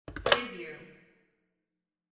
Phone Receiver Pick Up Wav Sound Effect #2
Description: The sound of picking up a telephone receiver
Properties: 48.000 kHz 16-bit Stereo
Keywords: phone, receiver, telephone, pick, picking, up, lift, lifting, answer, answering
phone-pick-up-preview-2.mp3